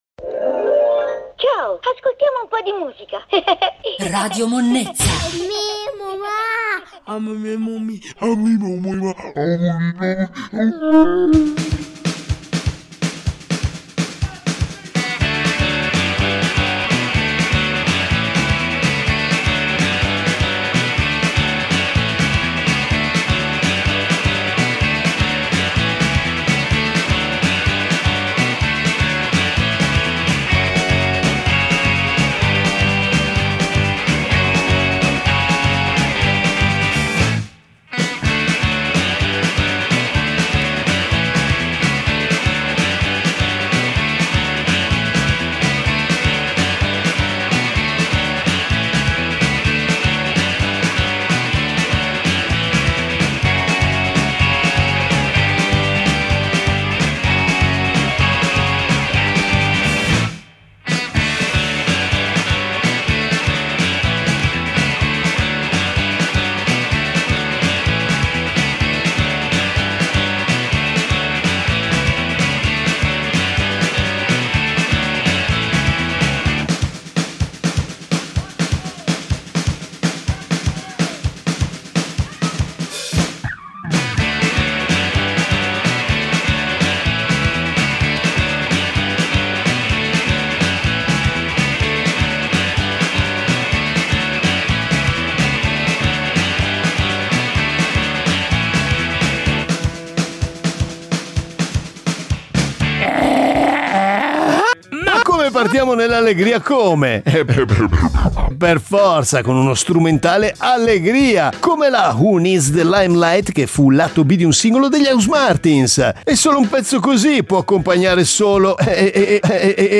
Senti il suono di pifferi, banjos, chitarre con la rumenta e brami per incollare una pinta di estratto al luppolo maggiorato anche se hai già il fegato che fa la ola?!?!